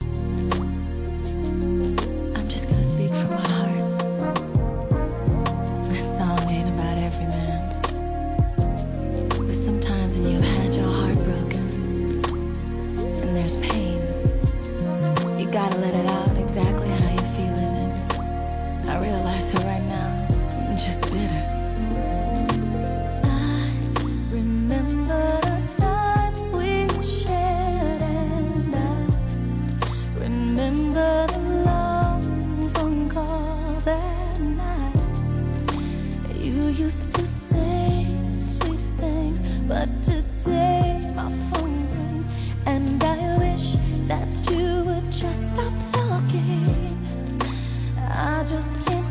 HIP HOP/R&B
透き通るヴォーカルでしっとりと歌い上げます!